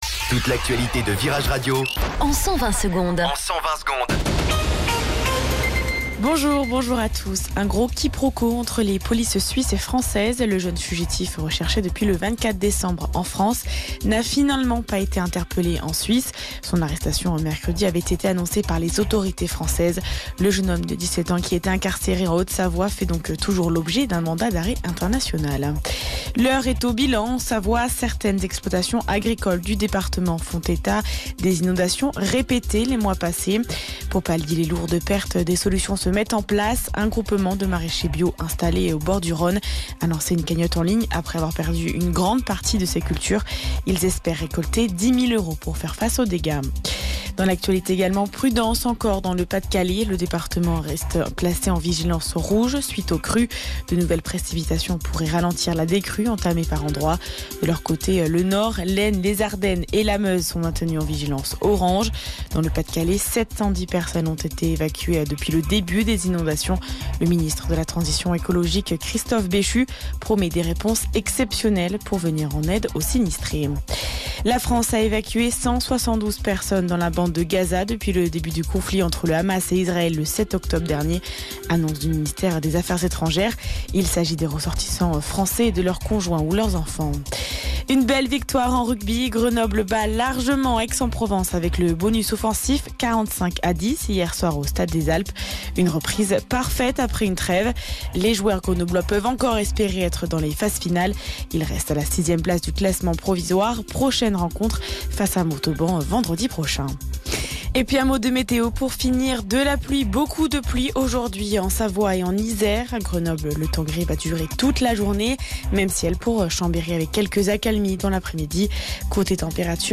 Flash Info